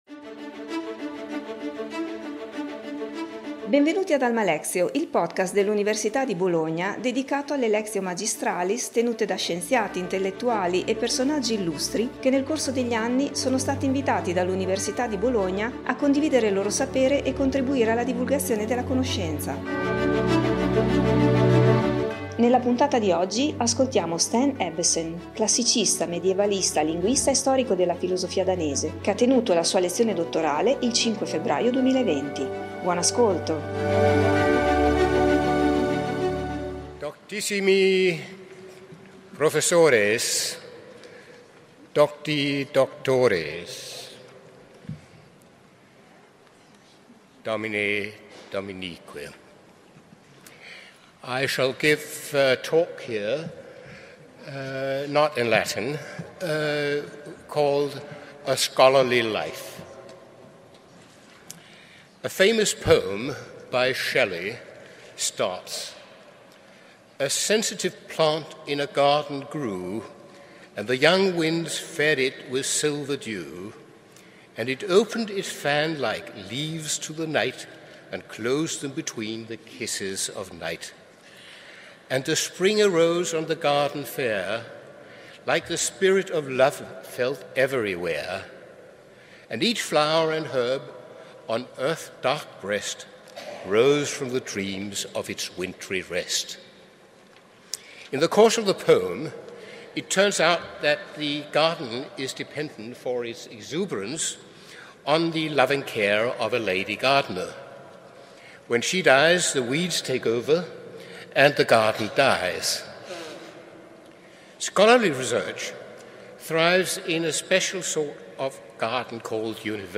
Lectio Magistralis